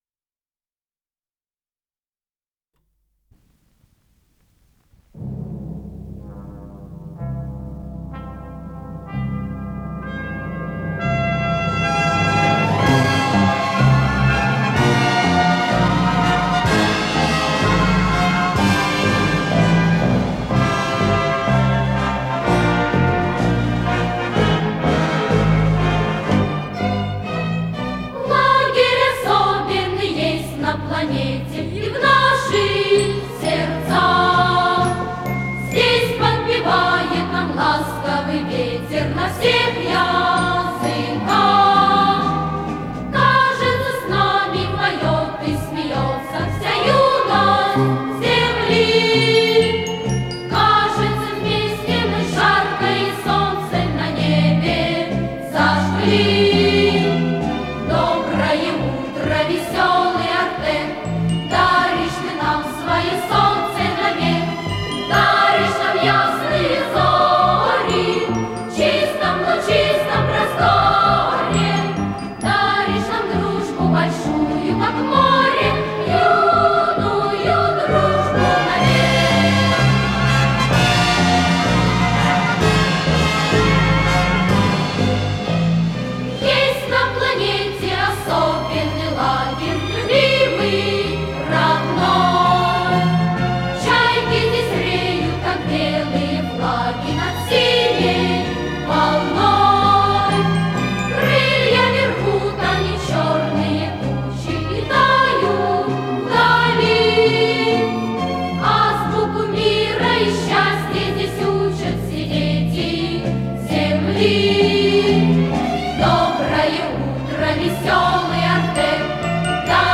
с профессиональной магнитной ленты
АккомпаниментСимфонический оркестр
ВариантДубль моно